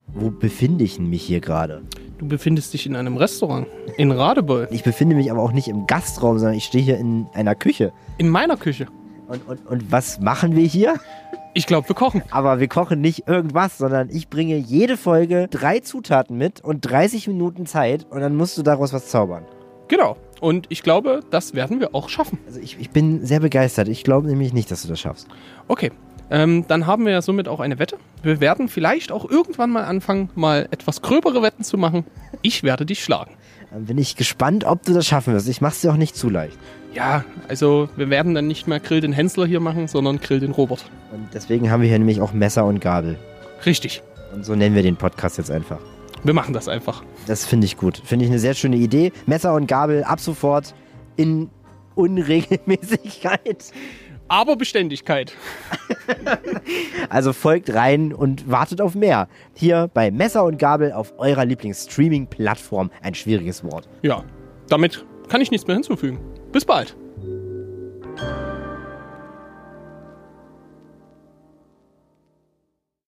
Trailer: Messer und Gabel